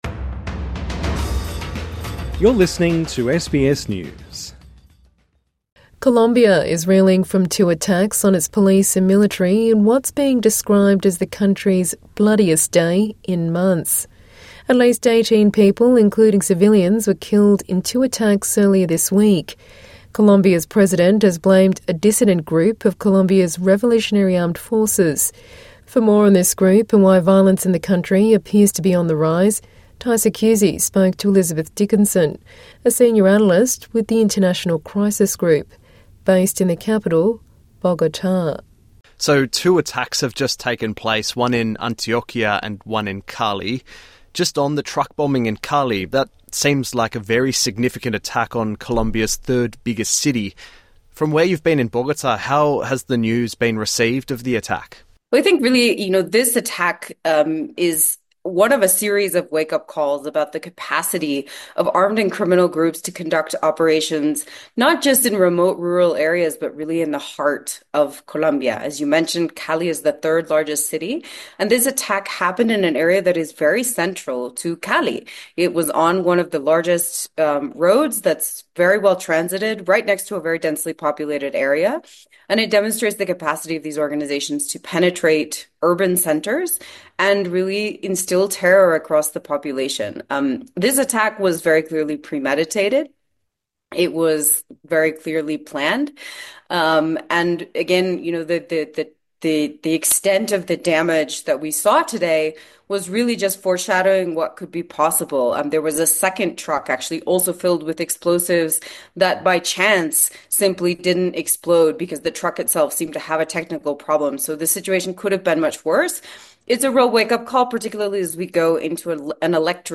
INTERVIEW: Two deadly attacks in Colombia expose security vulnerabilities: analyst